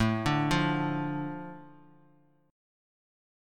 Asus4#5 chord